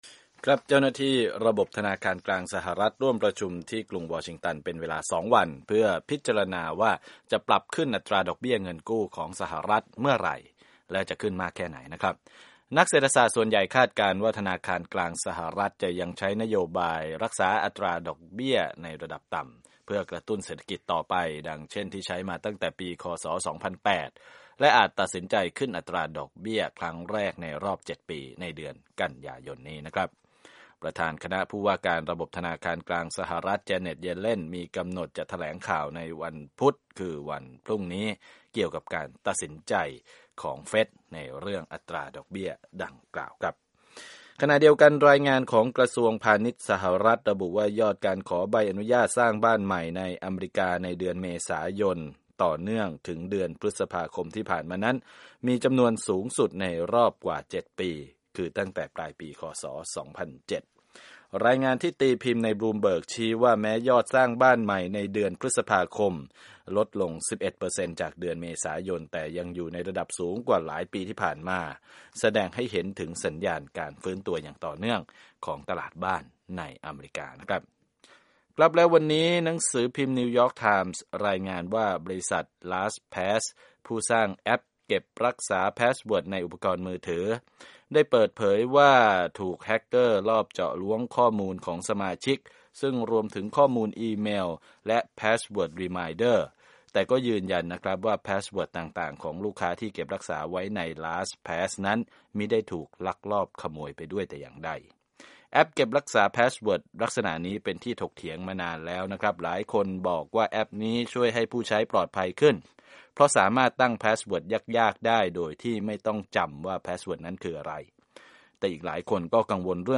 รวมข่าวธุรกิจ 6/16/15